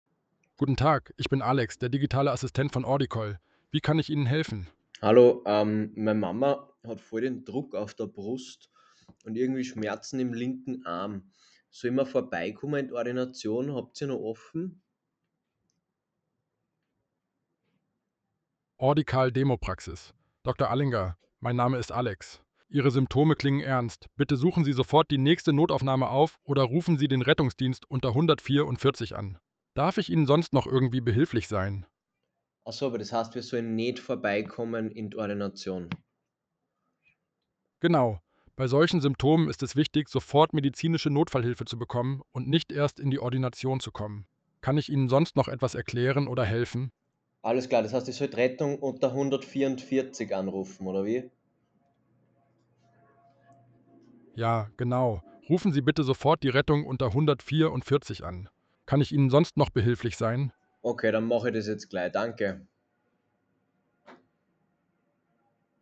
In dieser Hörprobe wird demonstriert, wie Ordicall Notfälle erkennt und passende Handlungsanweisungen gibt.